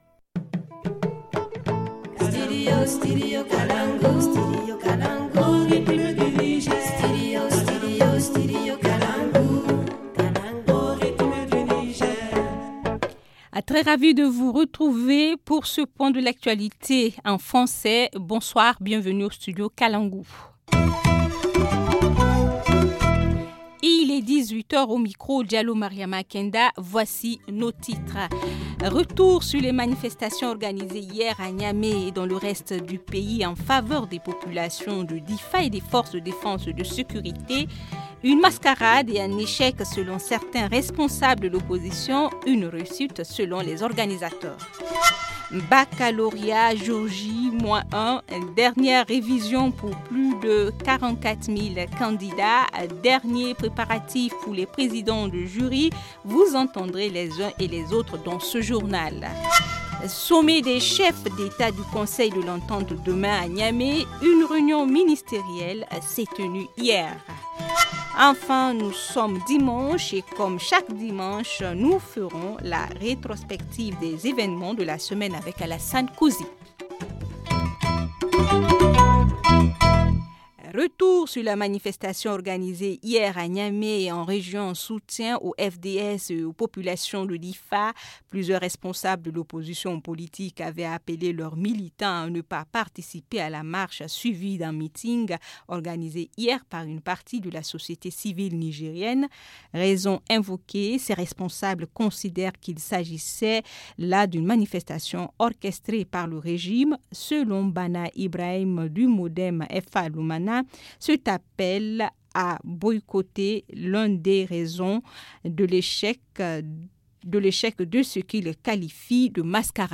Journal du 10 juillet 2016 - Studio Kalangou - Au rythme du Niger
Vous entendrez les uns et les autres dans ce journal.